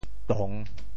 栋（棟） 部首拼音 部首 木 总笔划 9 部外笔划 5 普通话 dòng 潮州发音 潮州 dong3 文 潮阳 dong3 文 澄海 dong3 文 揭阳 dong3 文 饶平 dong3 文 汕头 dong3 文 中文解释 潮州 dong3 文 对应普通话: dòng ①房屋的脊檩，喻能担负重任的人：～梁 | ～宇 | 画～雕梁。